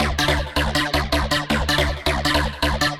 Index of /musicradar/future-rave-samples/160bpm
FR_Boingo_160-D.wav